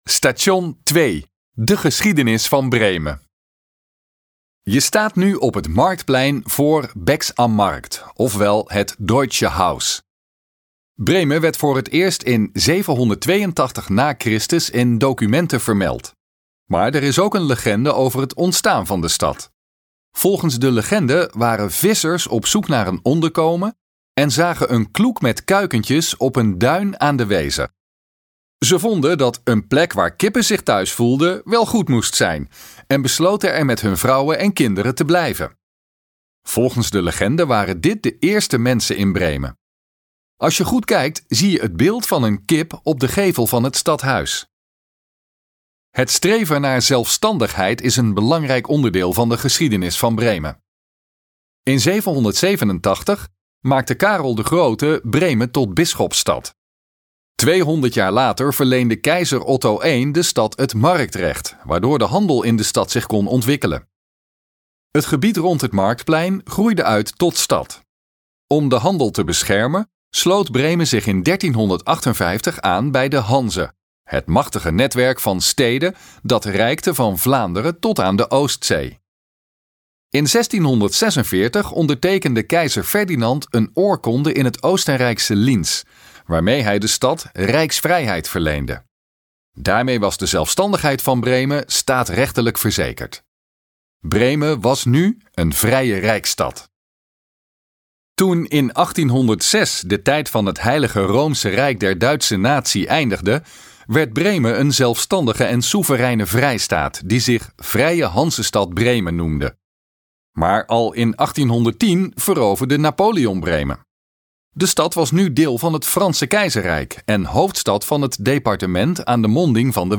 Gratis audiogids: Een wandeling door de historische binnenstad van Bremen